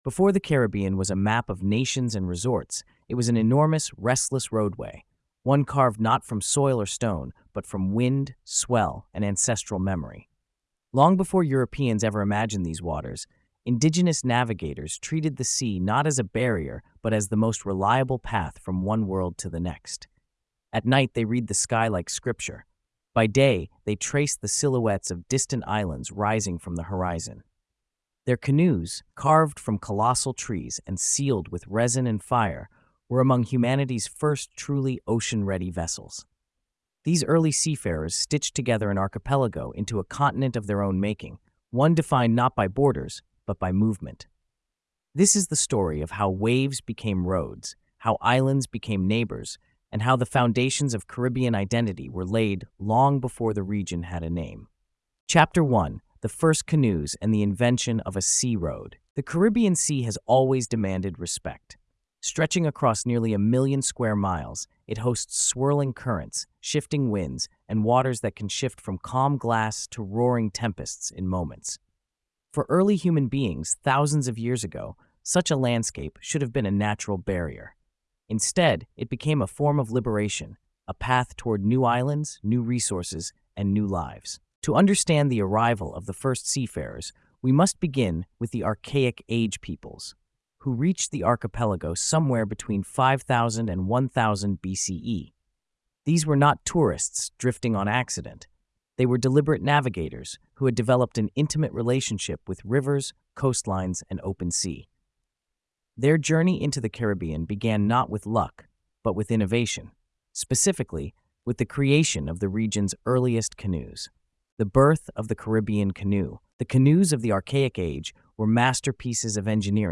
This documentary unfolds as a sweeping cultural history of how the Caribbean Sea—long before colonization, sugar empires, or the transatlantic slave system—became one of humanity’s earliest highways.